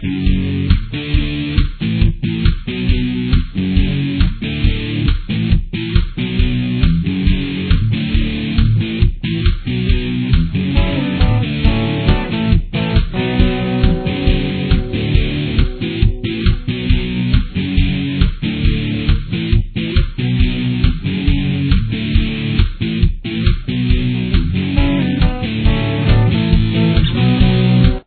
Verse